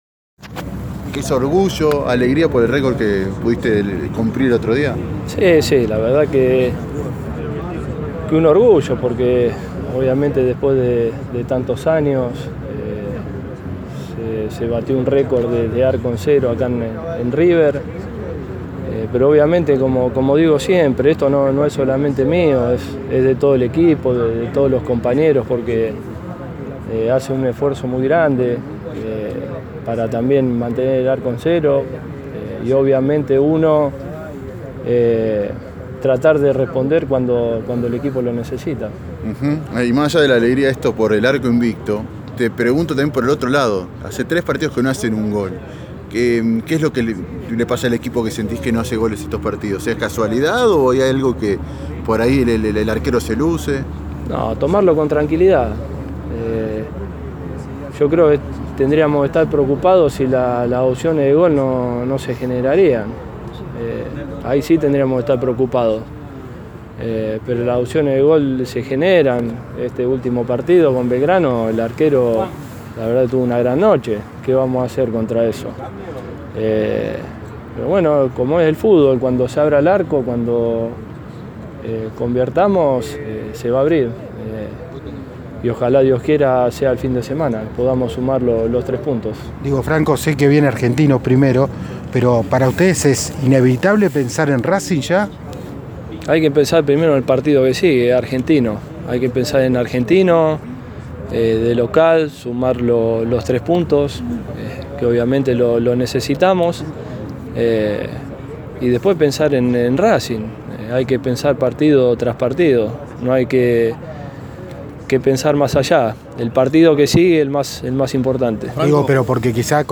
Armani, el arquero récord de River, manifestó sus sensaciones tras la práctica matutina del millonario.